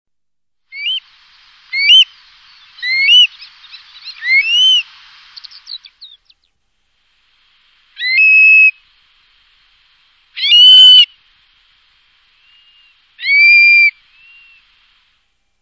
son chant si particulier !
oedicneme.criard.1.mp3